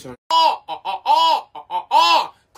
yuuponaaa Meme Sound Effect